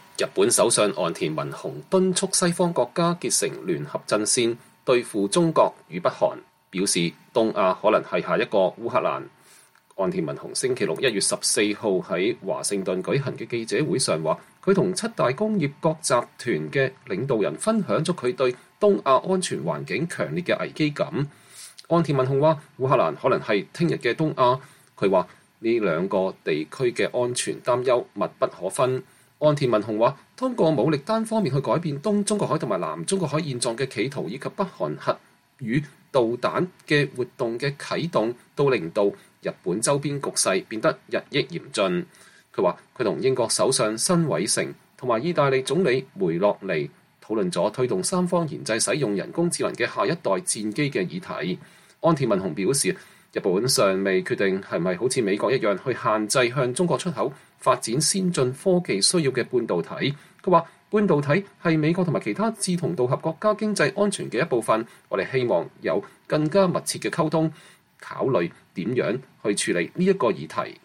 日本首相岸田文雄在華盛頓一個記者會上講話。